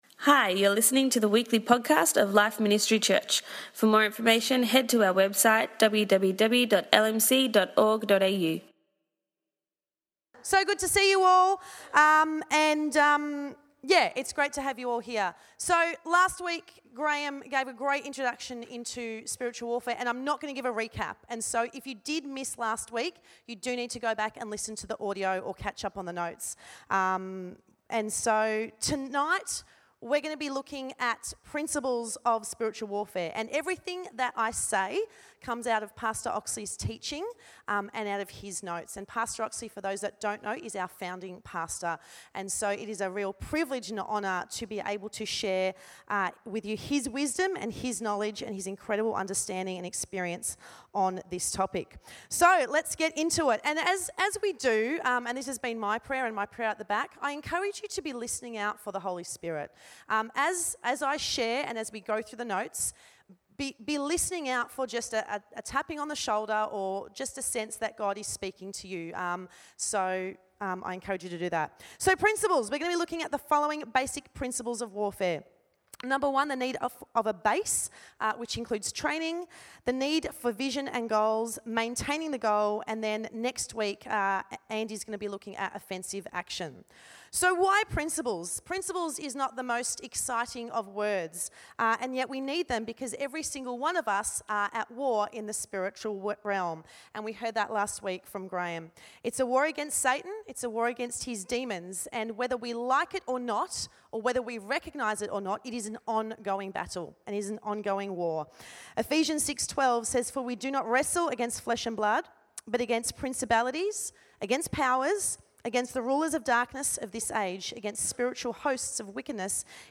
Tonight we continued our Deep Dive teaching series on The Spirit Realm with Part 2.